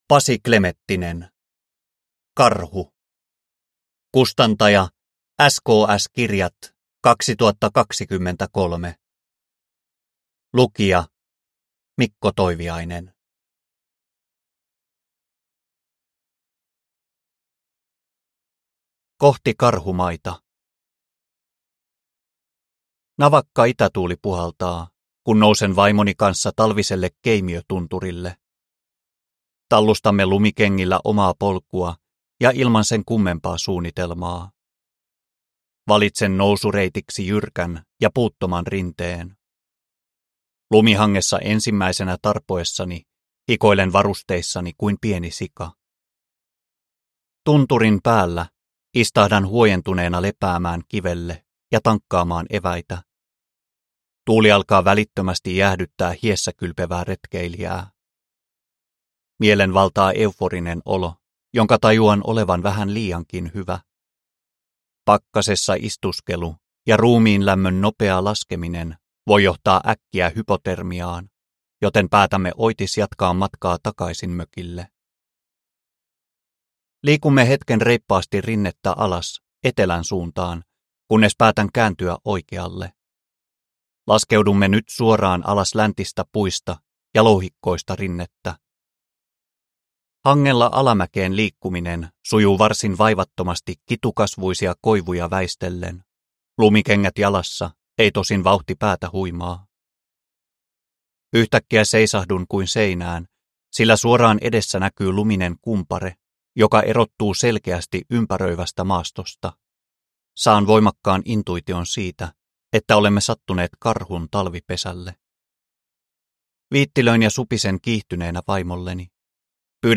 Karhu (ljudbok